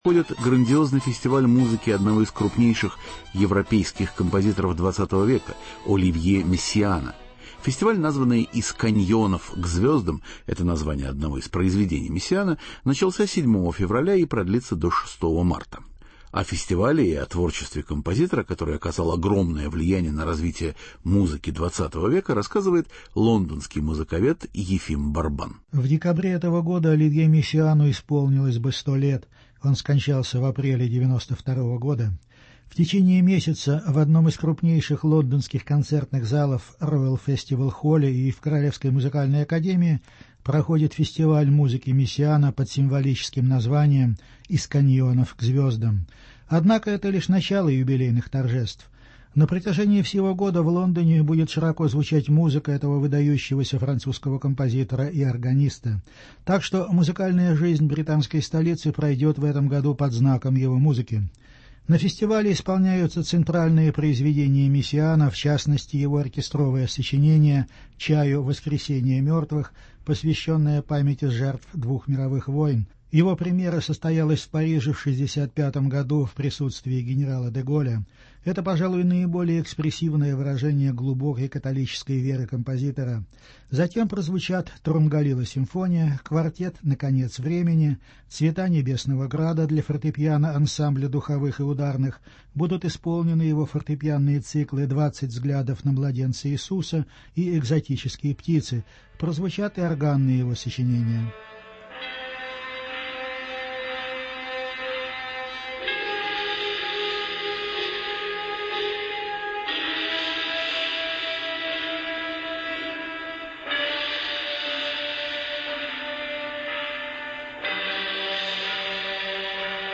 Беседа с композитором Оливье Мессианом.